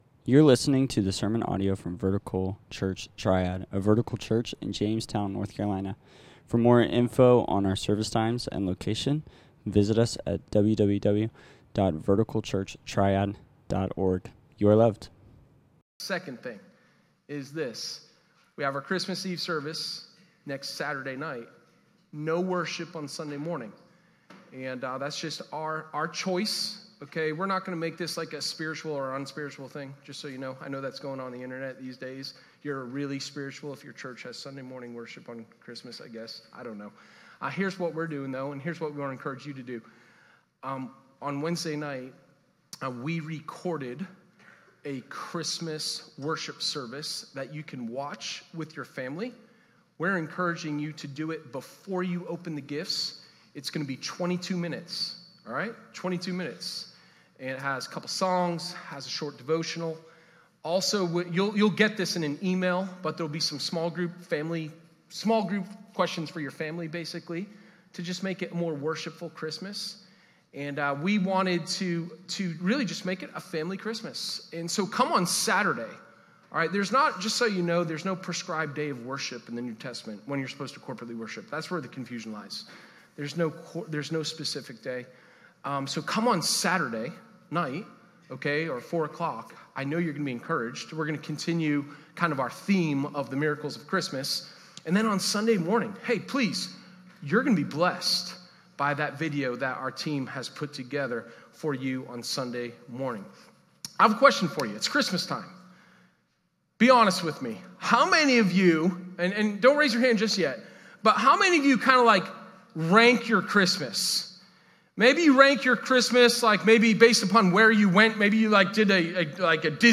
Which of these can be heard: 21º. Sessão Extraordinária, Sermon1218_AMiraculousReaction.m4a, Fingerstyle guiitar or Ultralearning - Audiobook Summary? Sermon1218_AMiraculousReaction.m4a